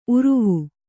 ♪ uruvu